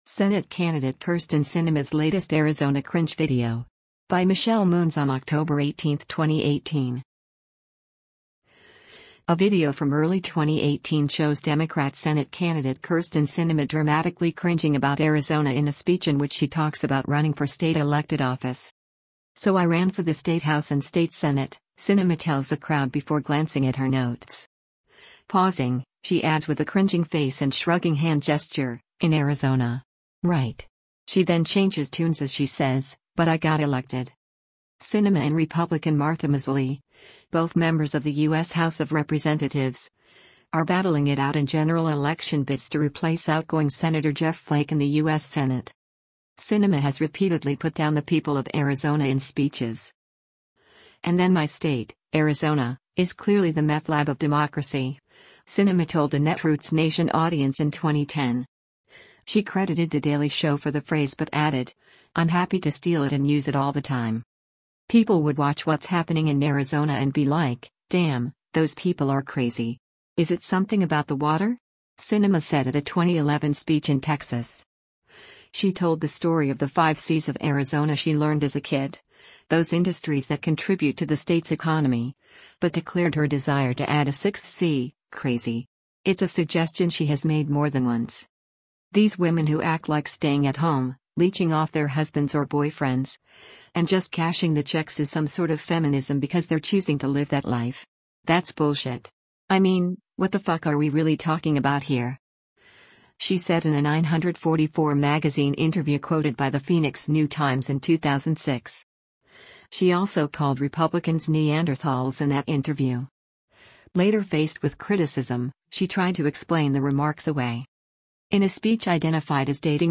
A video from early 2018 shows Democrat Senate candidate Kyrsten Sinema dramatically cringing about Arizona in a speech in which she talks about running for state elected office.
“So I ran for the state House and state Senate,” Sinema tells the crowd before glancing at her notes.